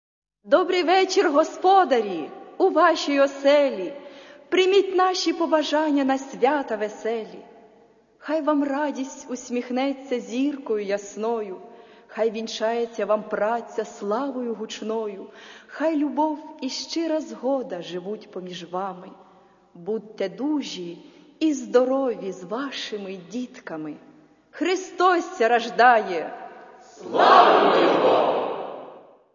Церковная